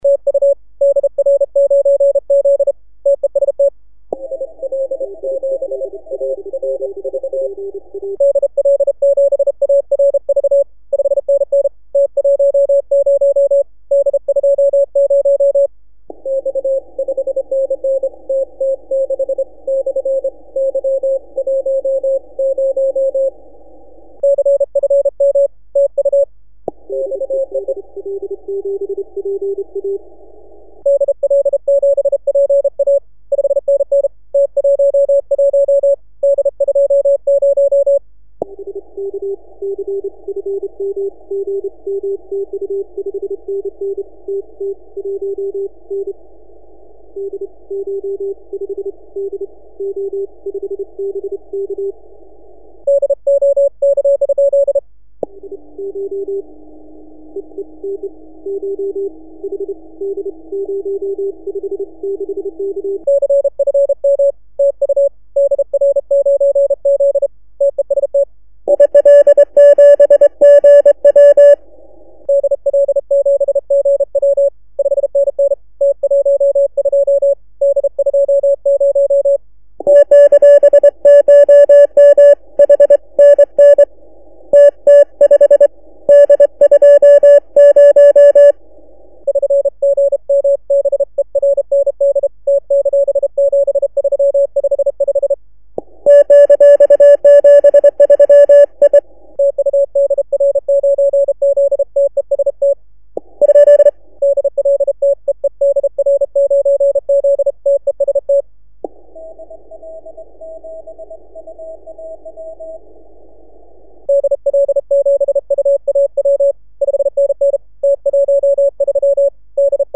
Wie jedes Jahr am 10m DARC Contest in der Klasse HP CW teilgenommen.
Contest Audio
Rig: IC-7700 mit 250 Hz Filter + DSP / Antenne FB33 Beam / microHAM TRX-CW Steuerung